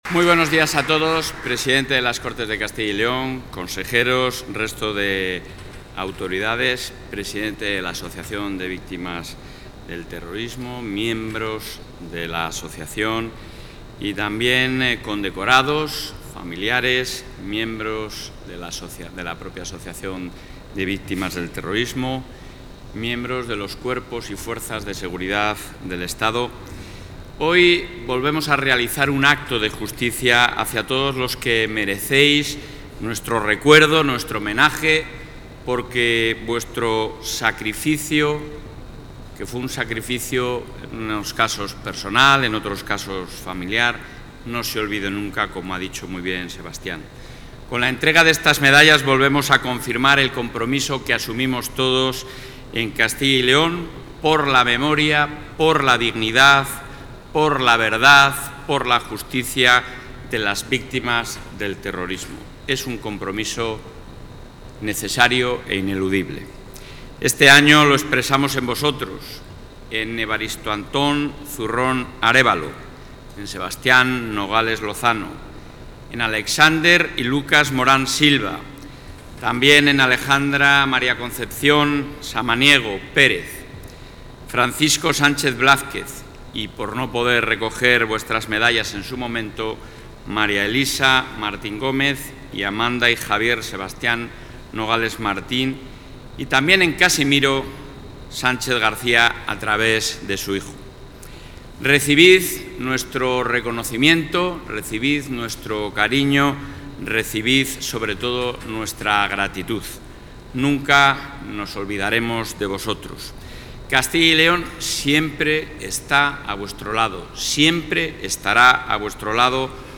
Intervención del presidente de la Junta.
El presidente de la Junta de Castilla y León, Alfonso Fernández Mañueco, ha participado hoy en Valladolid en los actos conmemorativos del 'Día de Recuerdo y Homenaje a las Víctimas del Terrorismo', donde ha reiterado el compromiso firme y permanente del Ejecutivo autonómico con la memoria, la dignidad y la justicia de todas las personas que han sufrido la violencia terrorista.